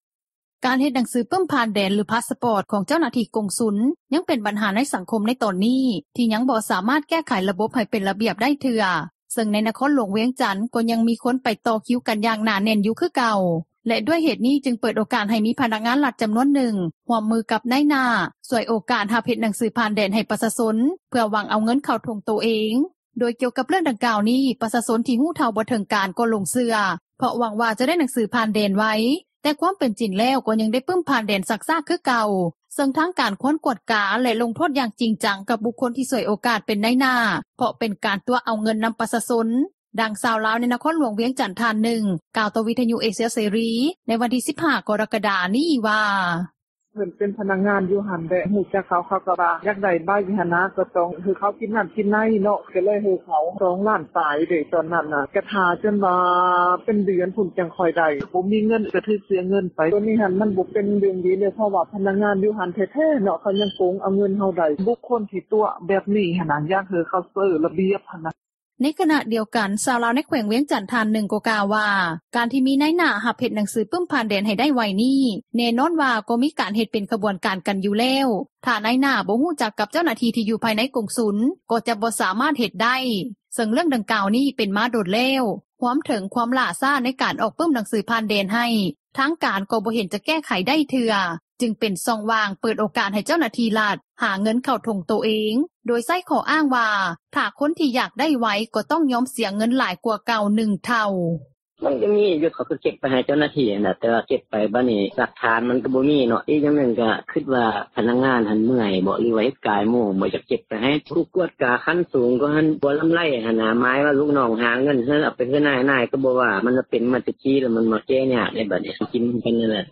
ດັ່ງຊາວບ້ານ ໃນນະຄອນຫຼວງວຽງຈັນ ທ່ານນຶ່ງ ກ່າວຕໍ່ວິທຍຸເອເຊັຽເສຣີ ໃນມື້ວັນທີ 15 ກໍຣະກະດາ ນີ້ວ່າ: